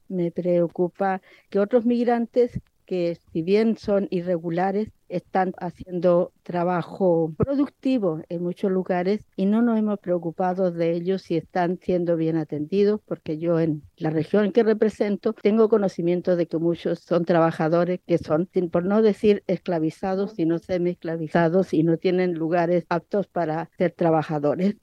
Por otro lado, la diputada independiente de la bancada del Frente Amplio, Clara Sagardía, solicitó que también se considere otro factor relacionado con la migración irregular que no tiene relación con la expulsión.